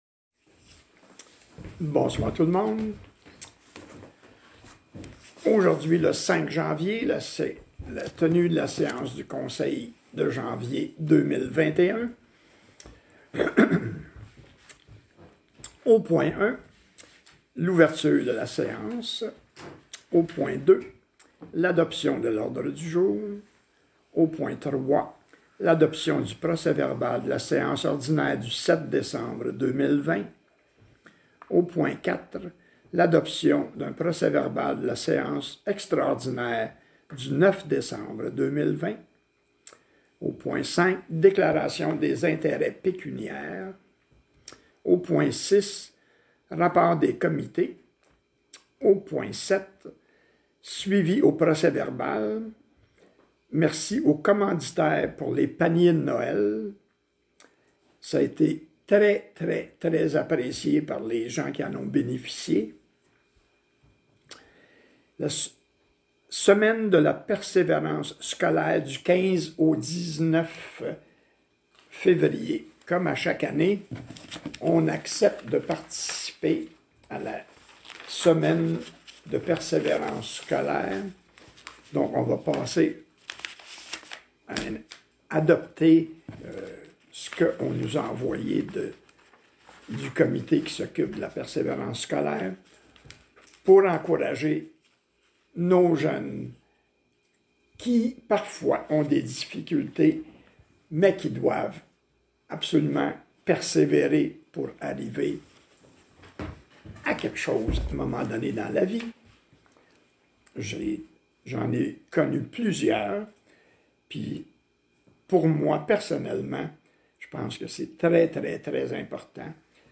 CONSEIL - séance ordinaire 5 Janvier 2021 – Municipalité de Saint-Louis-de-Blandford
conseil-5-janvier-2021-st-louis-blandford.mp3